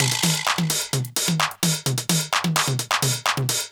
CLF Beat - Mix 14.wav